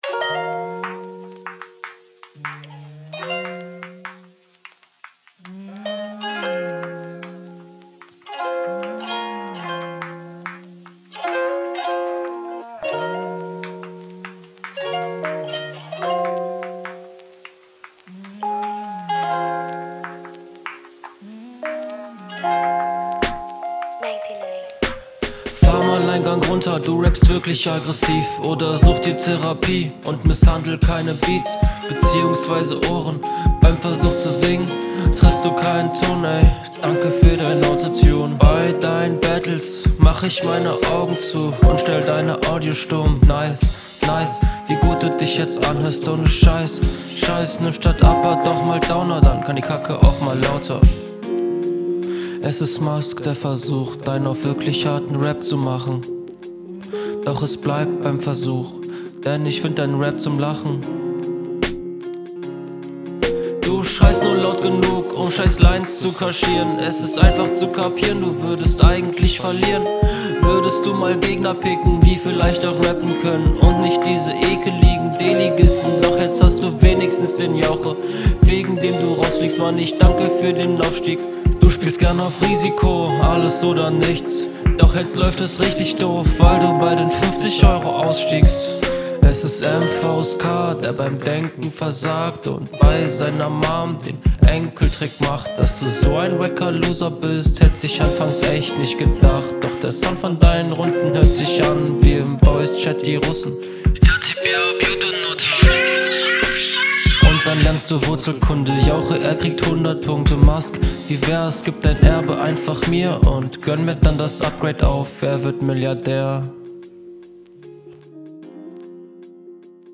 Teilweise ganz komisches Versmaß, aber hat Potential.